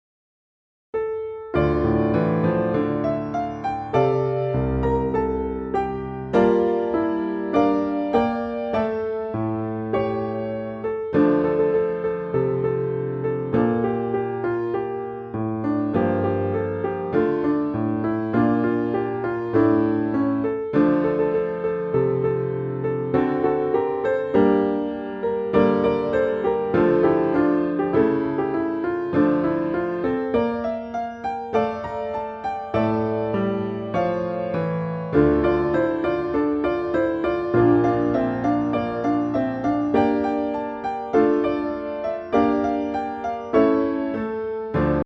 Listen to a sample of the instrumental.